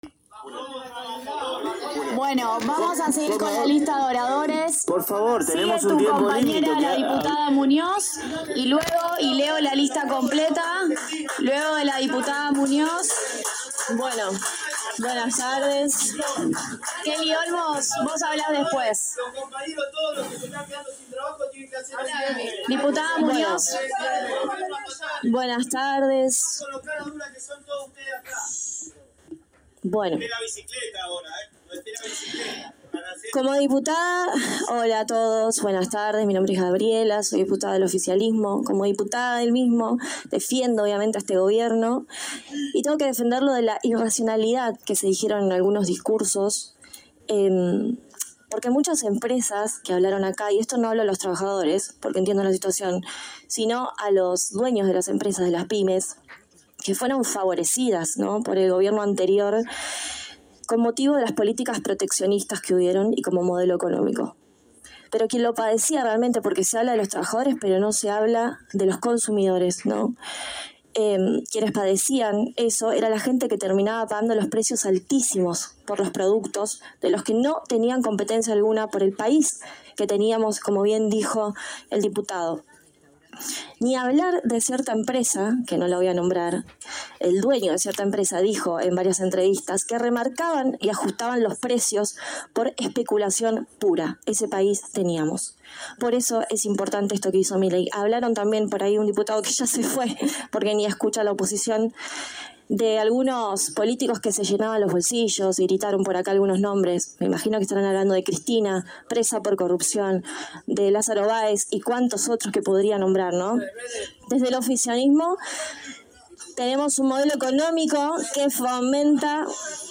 COMISIÓN DE ECONOMÍA – CÁMARA DE DIPUTADOS DE LA NACIÓN
El audio a continuación es de la Diputada nacional Gabriela Muñoz de La Libertad Avanza defendiendo lo indefendible.